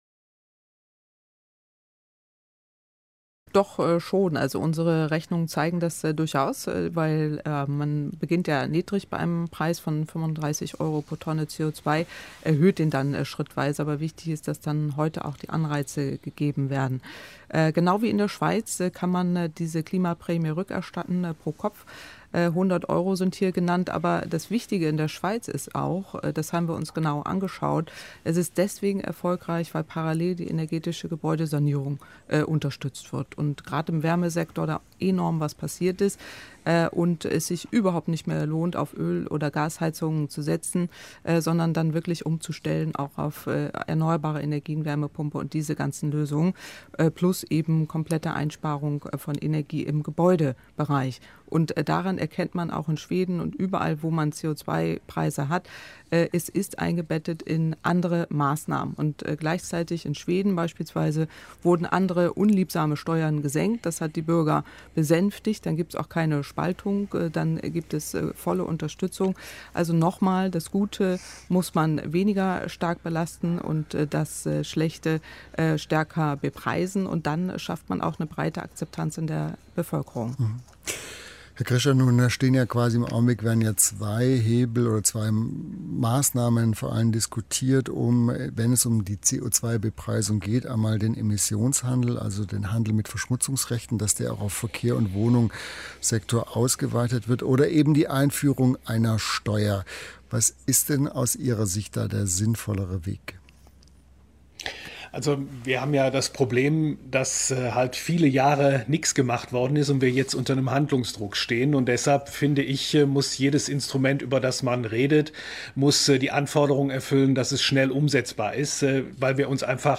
Hören Sie unten einen Ausschnitt aus der Sendung Kontrovers vom 22.7.2019 des Dlf.